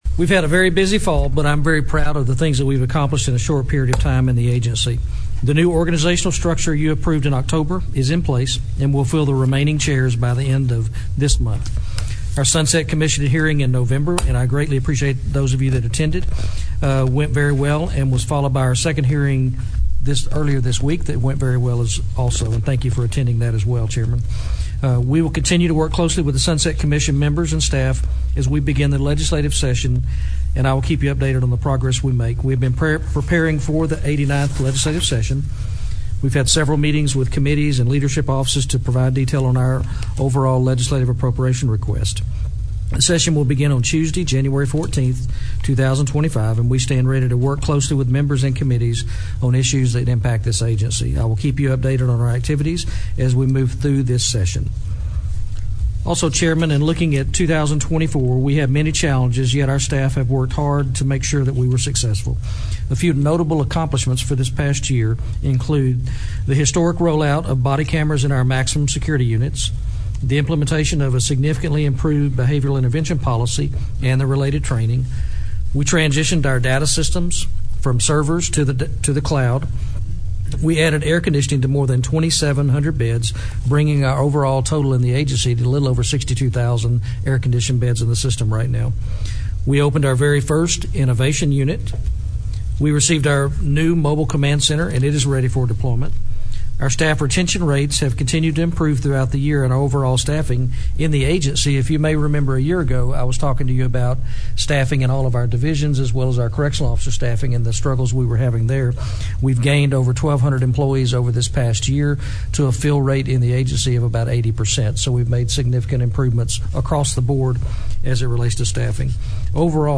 Click below to hear comments from Bryan Collier during the December 13, 2024 meeting of the Texas board of criminal justice.